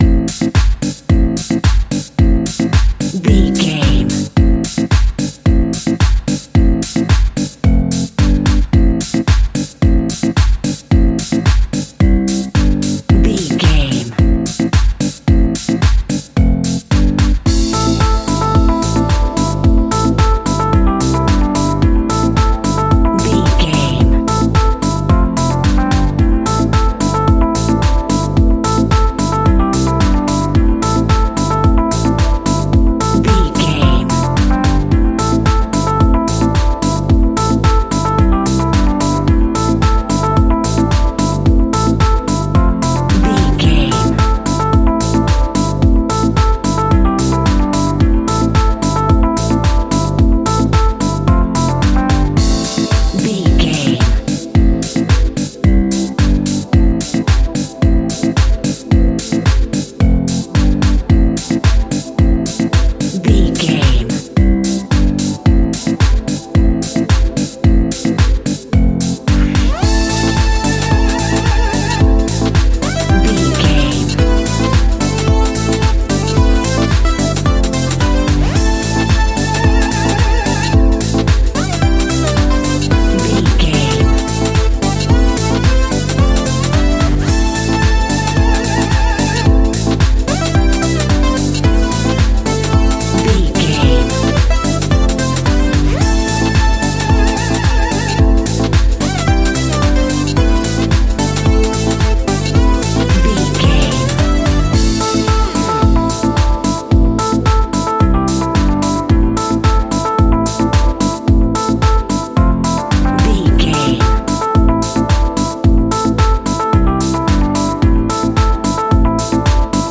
Funky Electro Cue.
Aeolian/Minor
funky
groovy
uplifting
energetic
cheerful/happy
synthesiser
bass guitar
drums
electric piano
drum machine
funktronica